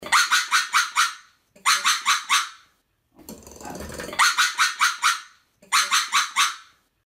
Звуки лемуров
10. Лает